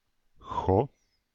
Ääntäminen
IPA: /kɔʂ/